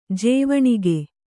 ♪ jēvaṇige